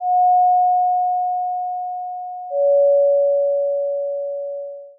DINGDONG
bell church ding dingdong dong door sound effect free sound royalty free Sound Effects